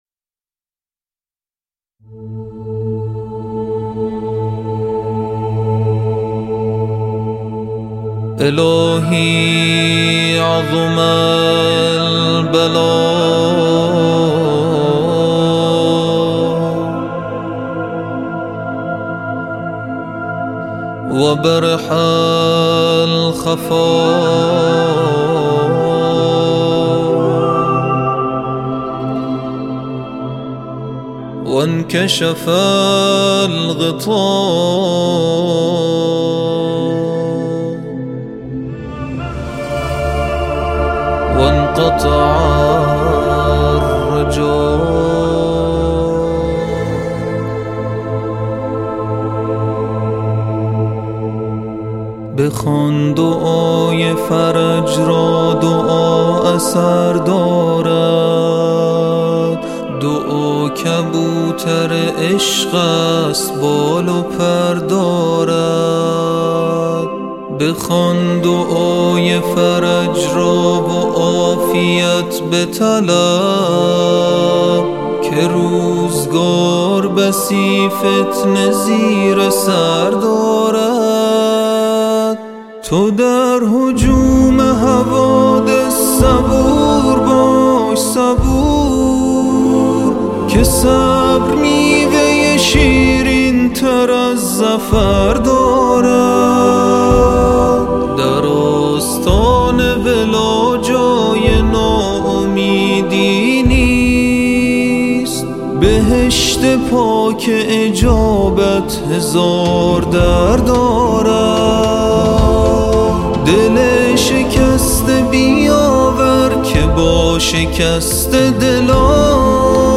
اثر استودیویی